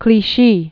(klē-shē)